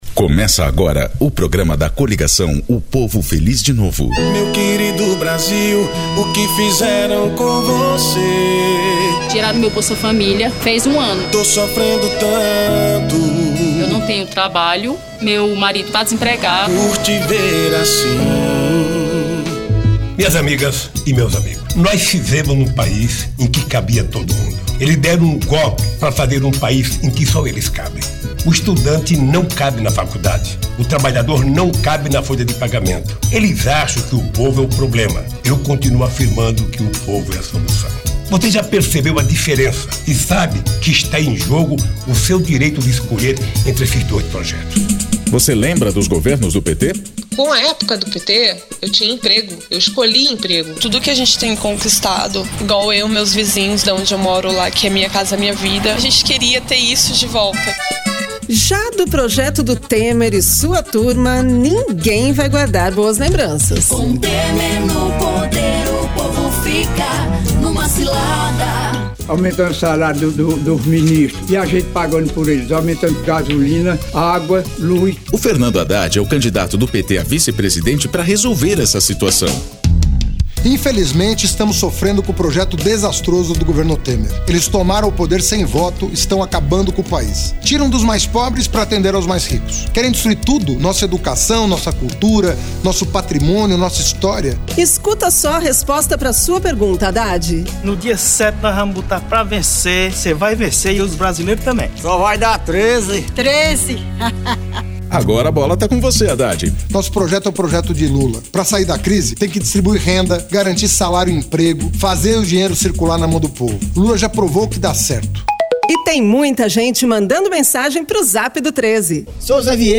Depoimentos : temático
Descrição Programa de rádio da campanha de 2018 (edição 04) - 1° turno